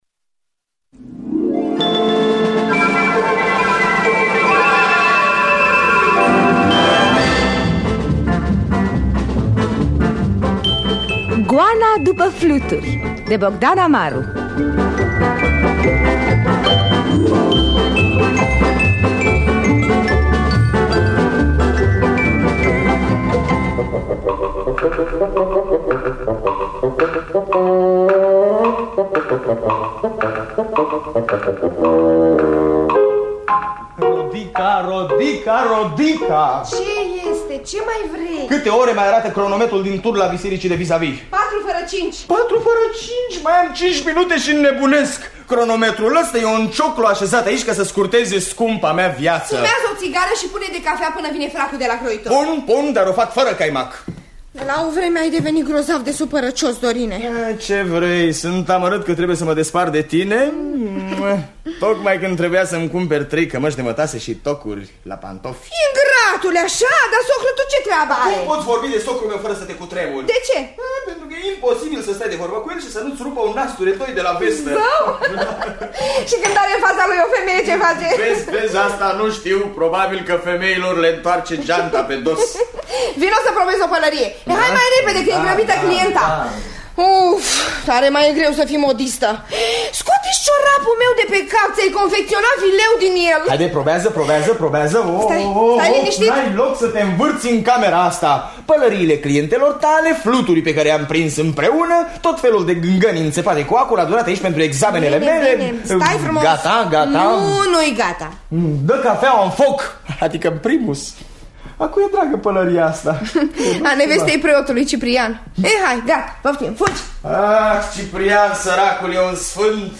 – Teatru Radiofonic Online
Înregistrare din anul 1968 (8 septembrie).